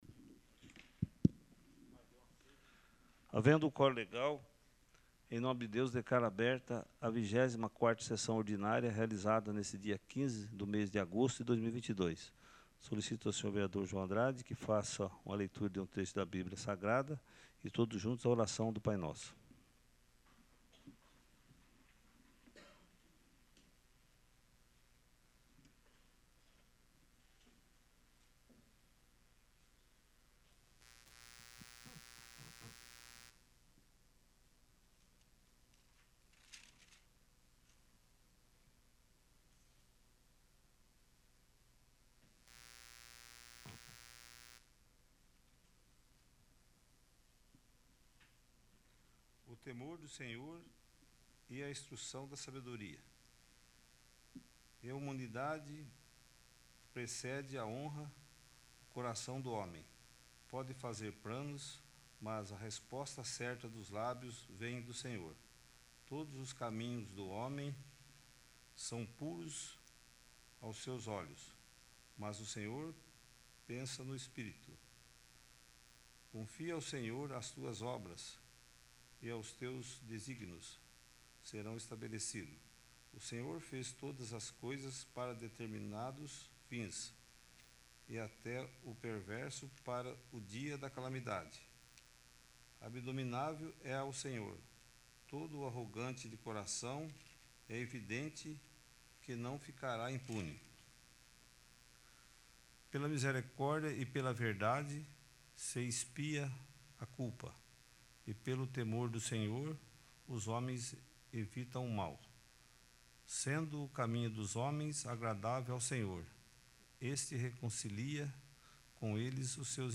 24º. Sessão Ordinária